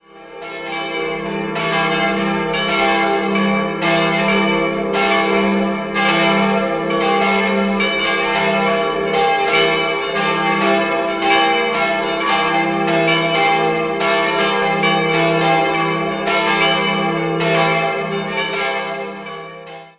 5-stimmiges Geläute: e'-g'-a'-c''-cis'' (hoch) Die kleine Glocke wurde 1889 von Stephan Hegendörfer in Amberg gegossen, die vierte entstand in der Gießerei Schilling (Apolda) im Jahr 1930 und die drei großen sind Werke der Firma Bachert aus dem Jahr 1958.